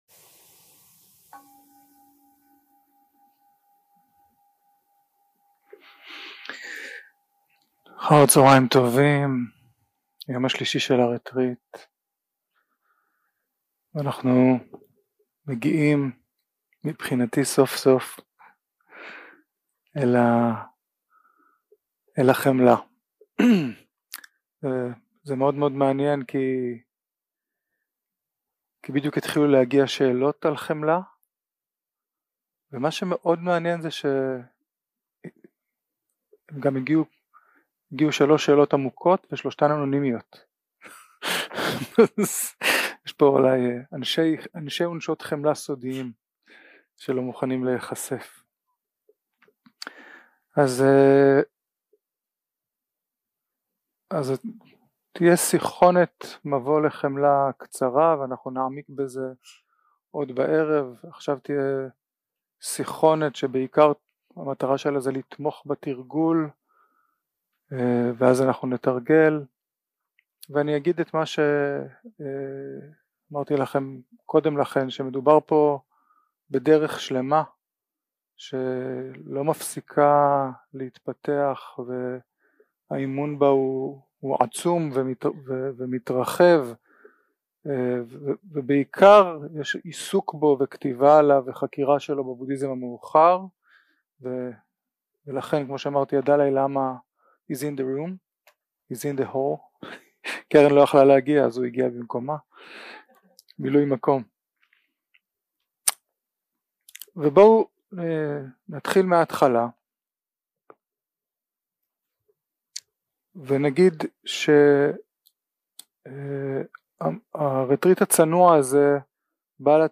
יום 3 – הקלטה 6 – צהריים – מדיטציה מונחית – חמלה לאדם אהוב, נייטרלי ולעצמי
יום 3 – הקלטה 6 – צהריים – מדיטציה מונחית – חמלה לאדם אהוב, נייטרלי ולעצמי 0:00 0:00 סוג ההקלטה: Dharma type: Guided meditation שפת ההקלטה: Dharma talk language: Hebrew